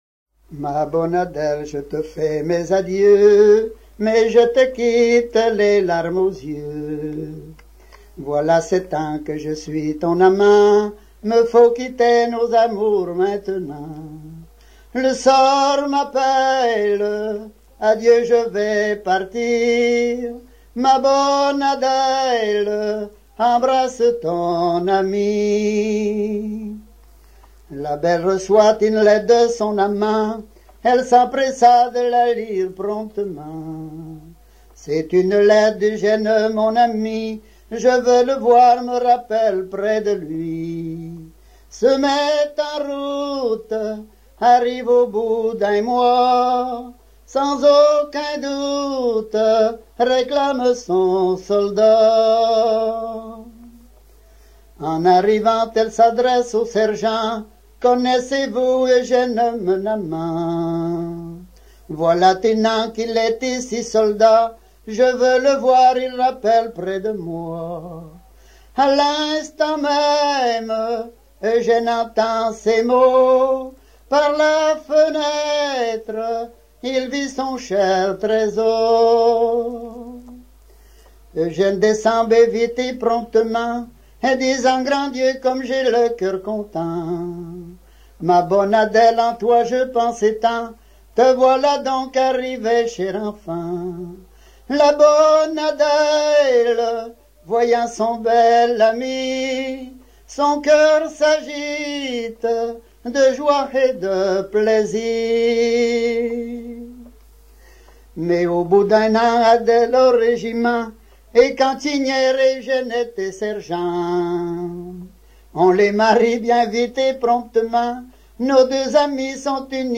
Localisation Falleron
Genre strophique